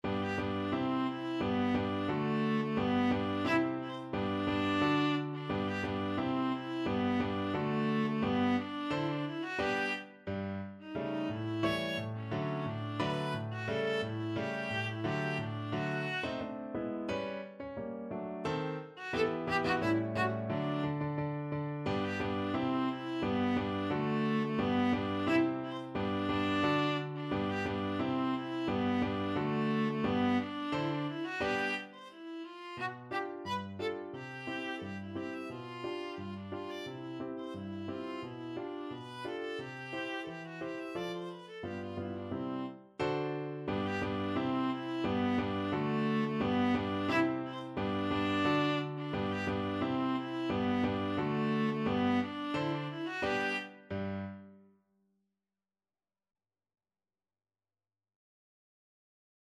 Free Sheet music for Viola
~ = 88 Stately =c.88
G major (Sounding Pitch) (View more G major Music for Viola )
2/4 (View more 2/4 Music)
Classical (View more Classical Viola Music)